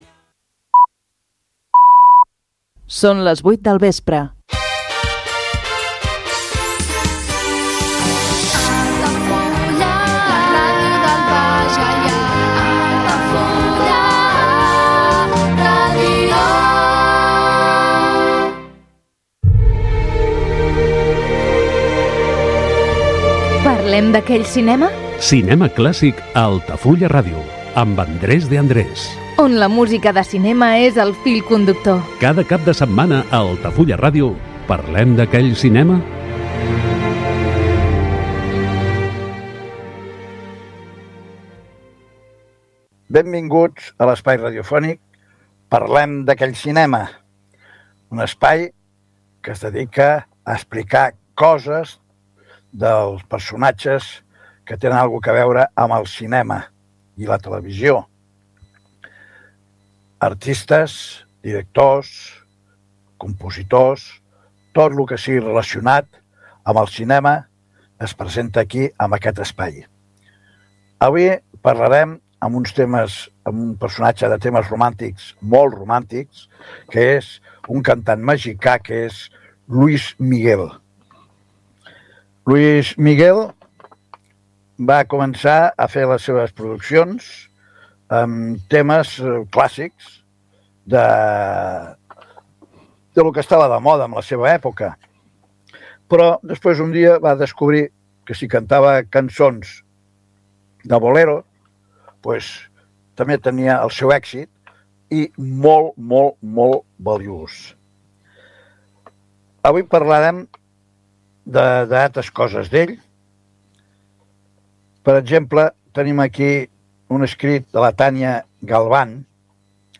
Programa de cinema clàssic produït per Altafulla Ràdio. La música de cinema és el fil conductor de l’espai